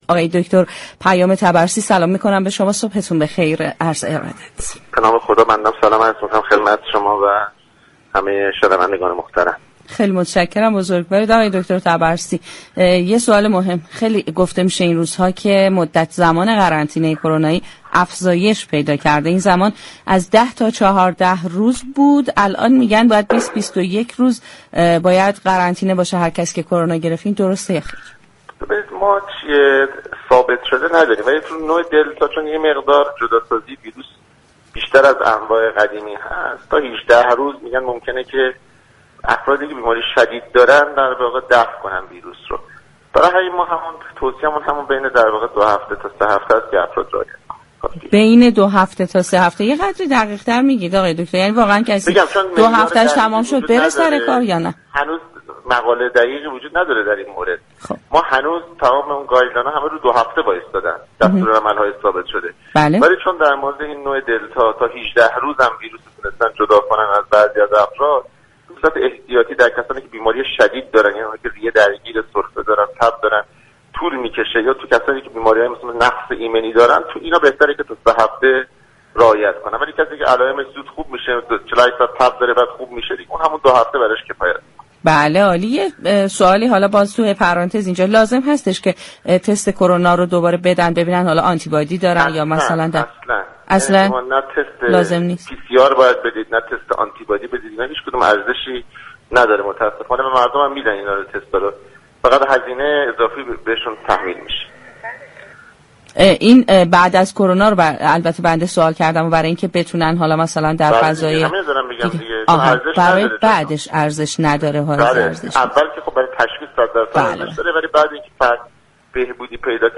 در گفتگو با برنامه تهران ما سلامت رادیو تهران